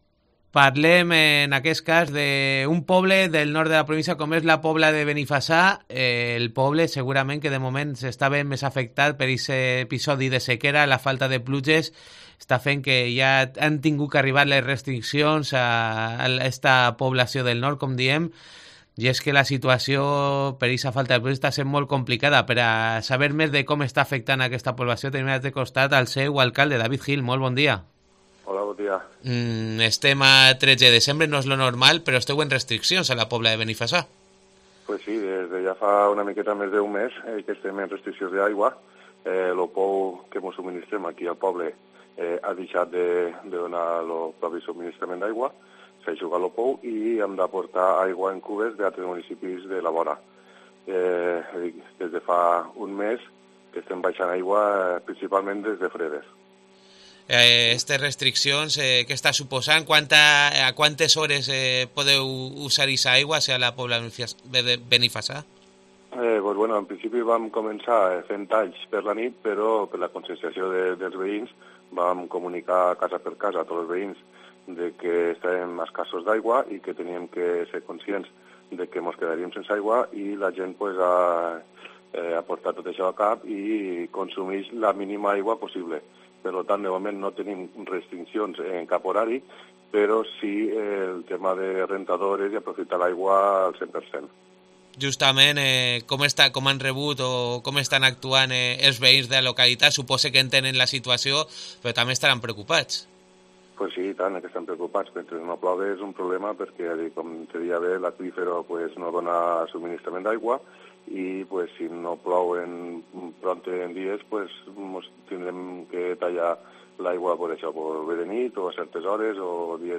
Entrevista a David Gil, alcalde de la Pobla de Benifassà